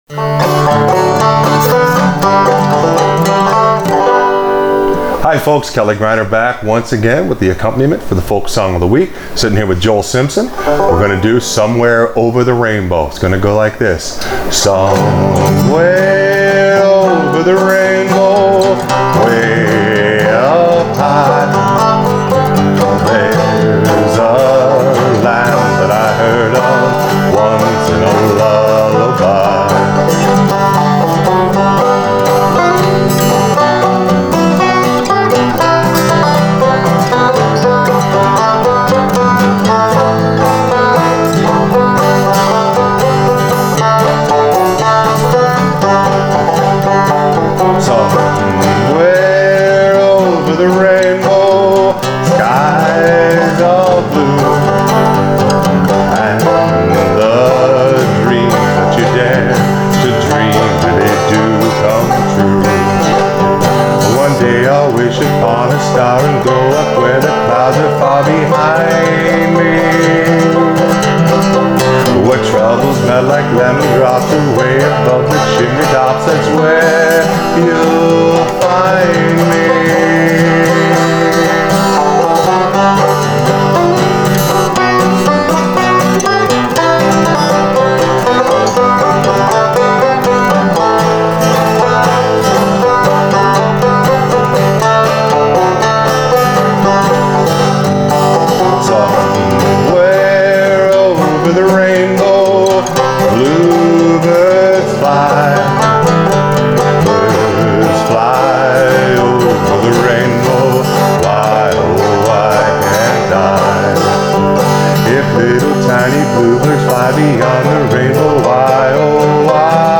Accompaniment for Frailing Banjo
It’s short and sweet and was a lot of fun to do.